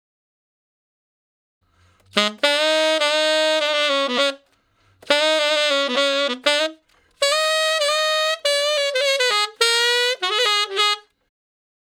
068 Ten Sax Straight (Ab) 13.wav